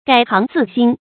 改行自新 注音： ㄍㄞˇ ㄏㄤˊ ㄗㄧˋ ㄒㄧㄣ 讀音讀法： 意思解釋： 改變行為，重新做人。